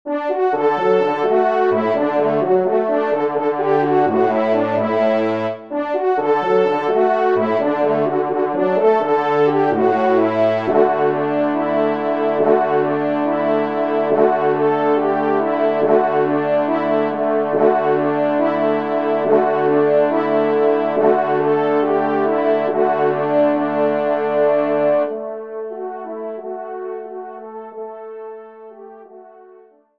Genre :  Musique Religieuse pour Trompes ou Cors en Ré
4e Trompe